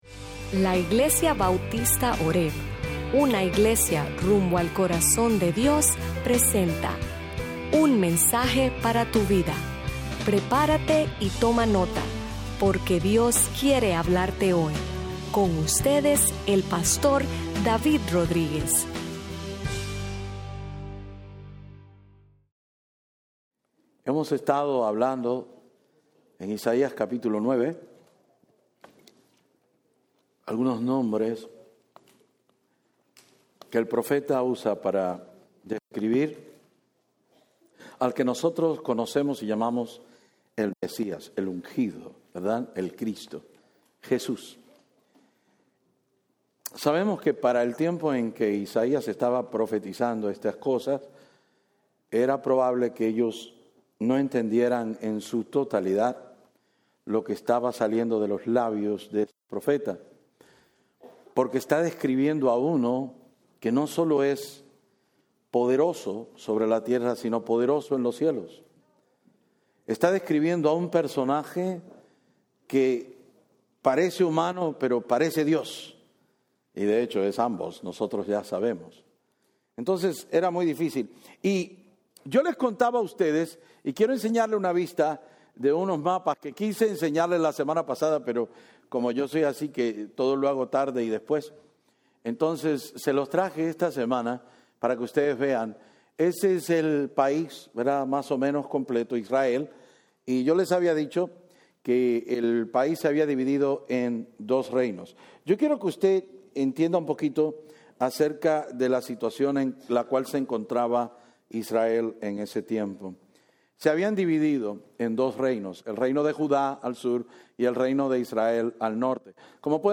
Sermons Archive - Page 88 of 154 - horebnola-New Orleans, LA